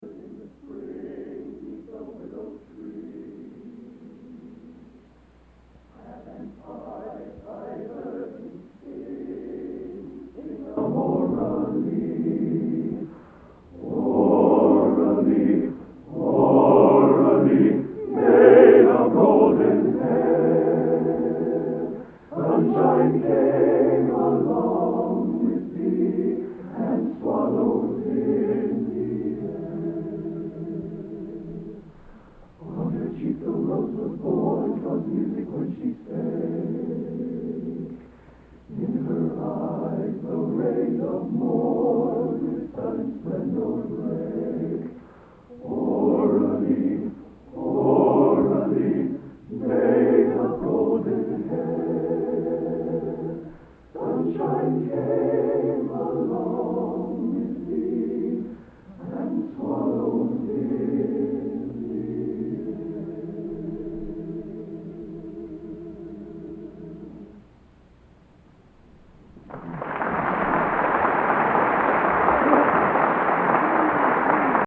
Collection: End of Season, 1987
Location: West Lafayette, Indiana
Genre: | Type: End of Season